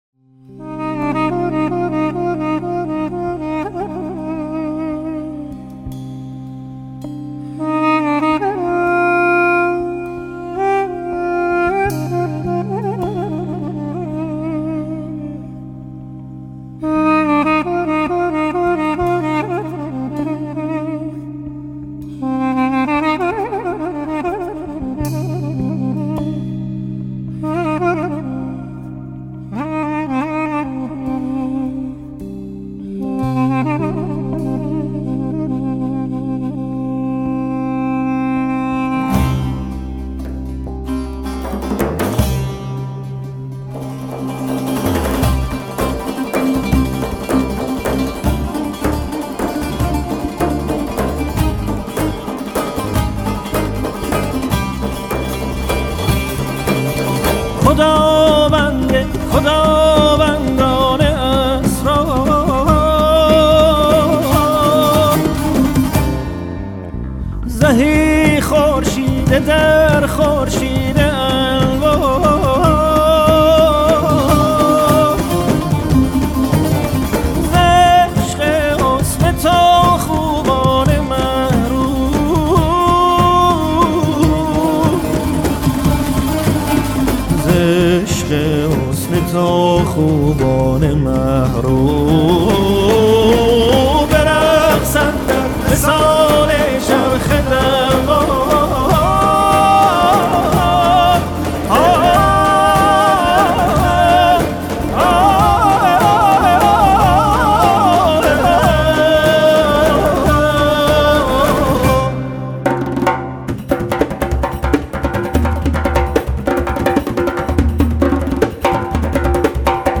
«ساز و آواز» بداهه در مقام اصفهان
بربت
دف
دودوک
گیتار بیس
تنبور